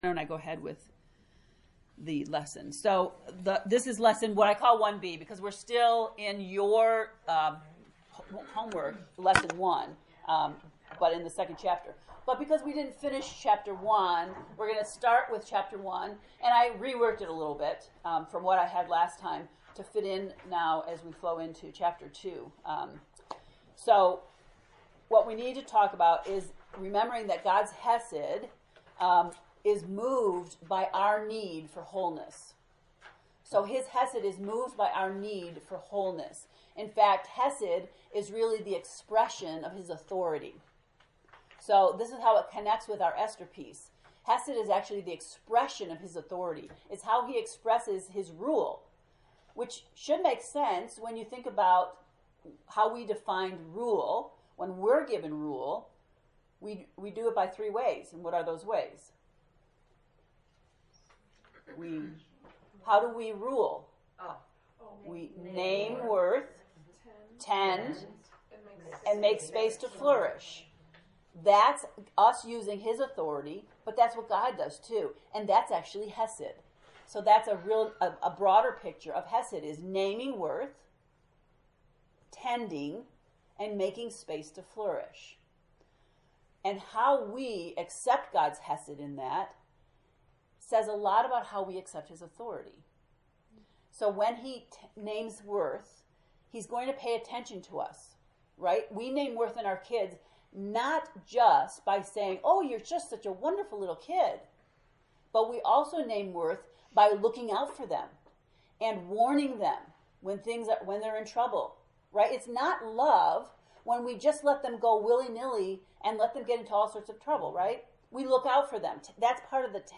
JONAH lesson 1B